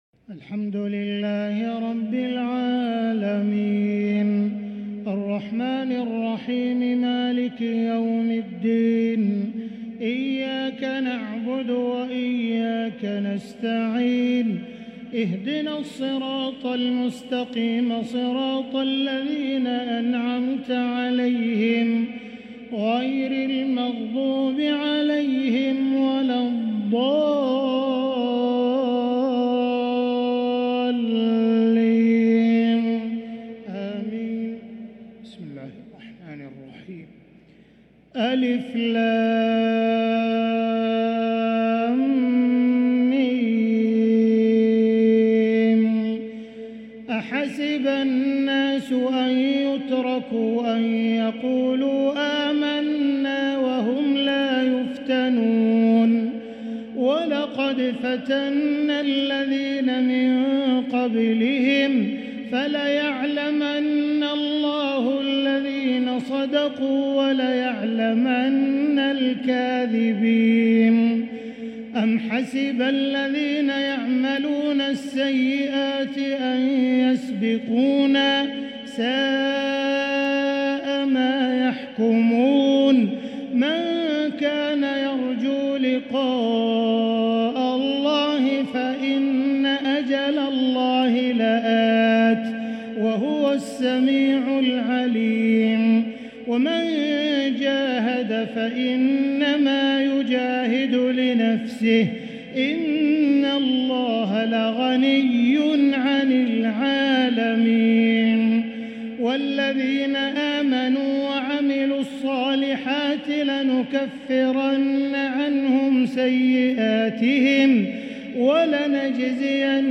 تهجد ليلة 23 رمضان 1444هـ فواتح سورة العنكبوت (1-35) | Tahajjud 23st night Ramadan 1444H Surah Al-Ankaboot > تراويح الحرم المكي عام 1444 🕋 > التراويح - تلاوات الحرمين